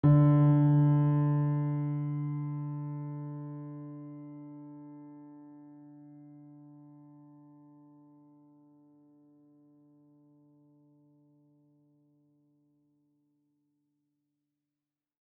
piano-sounds-dev
GreatAndSoftPiano